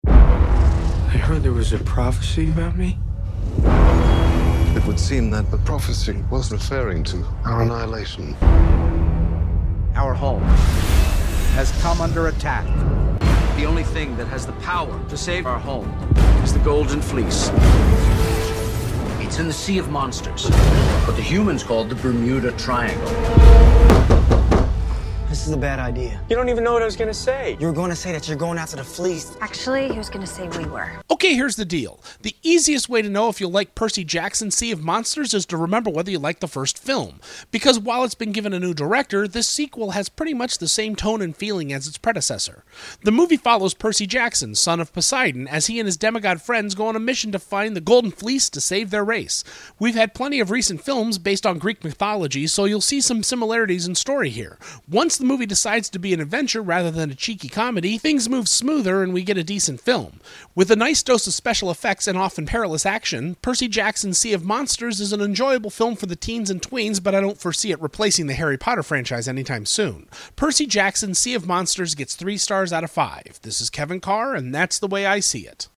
‘Percy Jackson: Sea of Monsters’ Movie Review